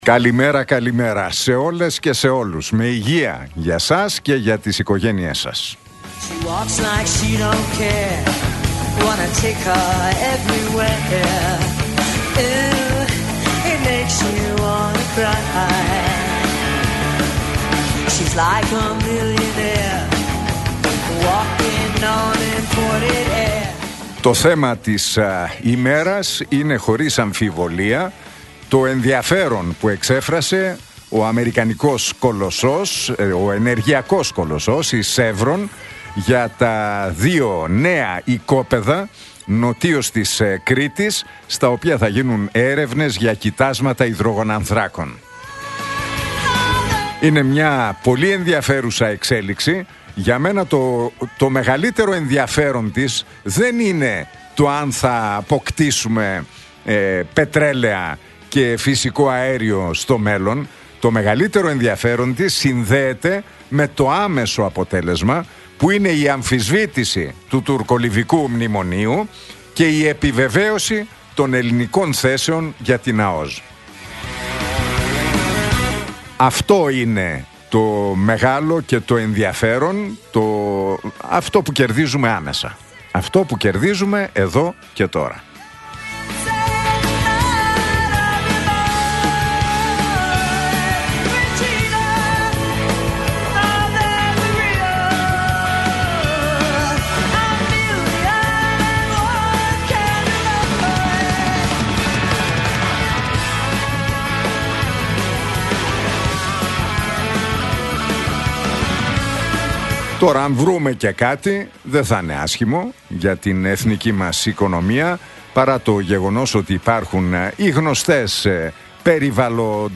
Ακούστε το σχόλιο του Νίκου Χατζηνικολάου στον ραδιοφωνικό σταθμό RealFm 97,8, την Πέμπτη 27 Μαρτίου 2025.